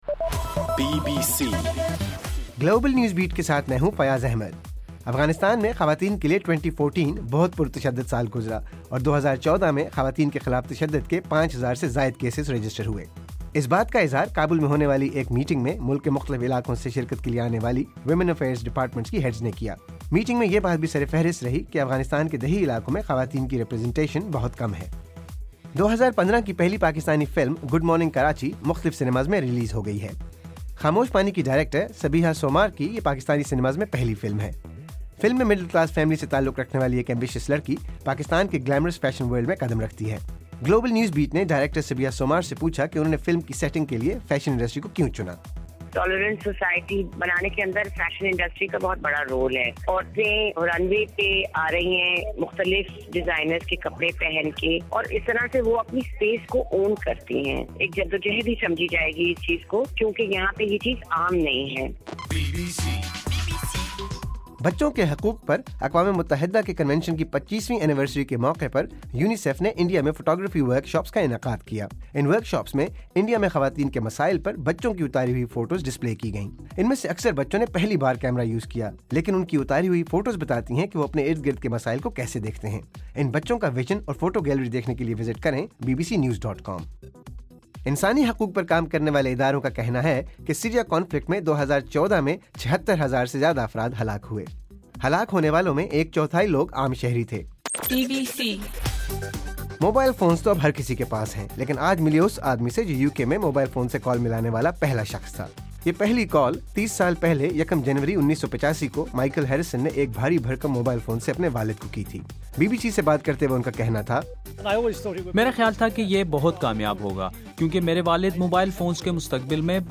جنوری 02: صبح 1 بجے کا گلوبل نیوز بیٹ بُلیٹن